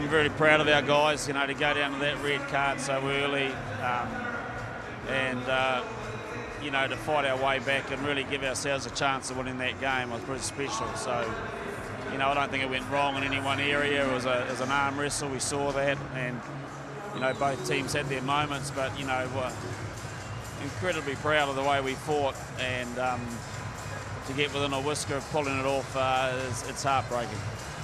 All Blacks coach Ian Foster says it was a heartbreaking loss.